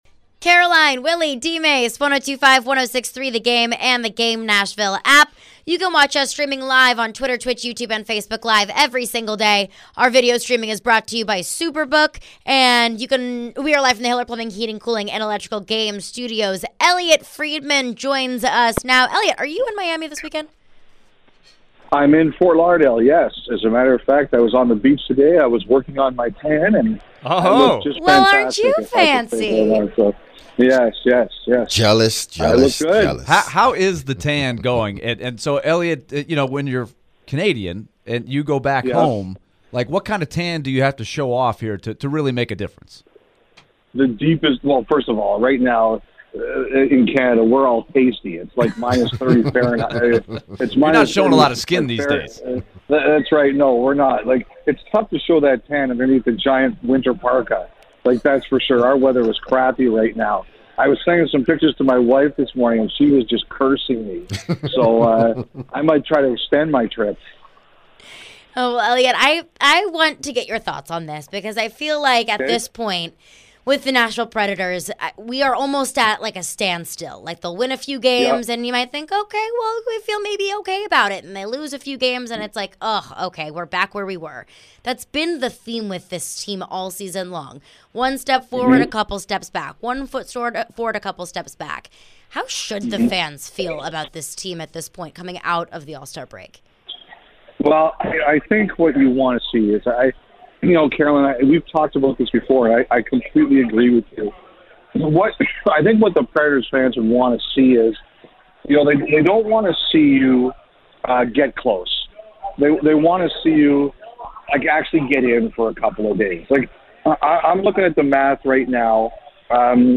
Elliotte Friedman Interview (2-2-23)